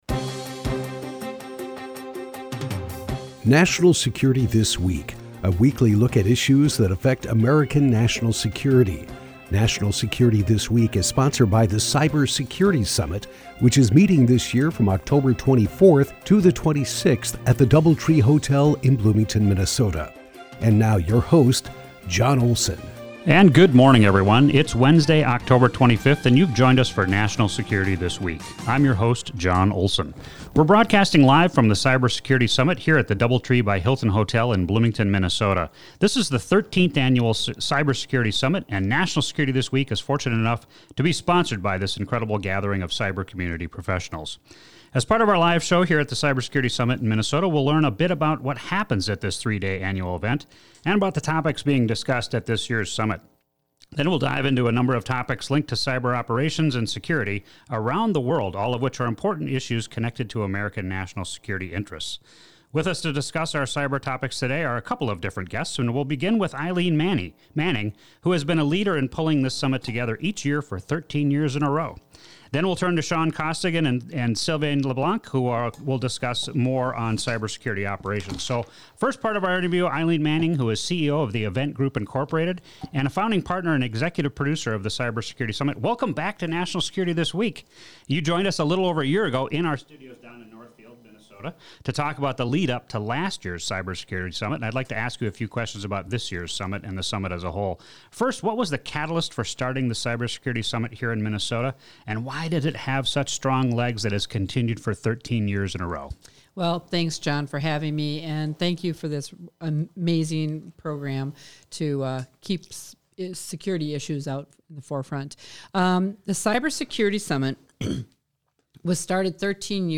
This week live from the 13th Annual Cyber Security Summit in Bloomington, Minnesota